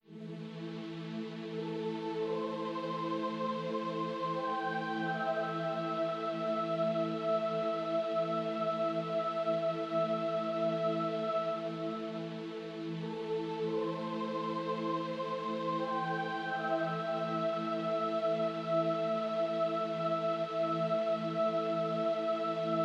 楼梯间脚步声
描述：脚步声在楼梯间回荡。
Tag: 脚步 楼梯间 电影 楼梯 声音